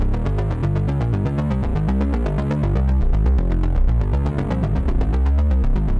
Index of /musicradar/dystopian-drone-samples/Droney Arps/120bpm
DD_DroneyArp1_120-C.wav